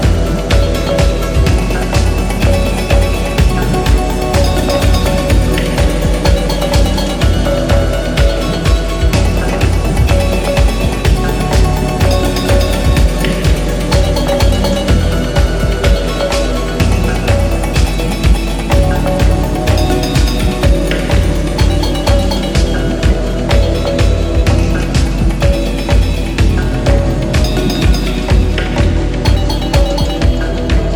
électro maloya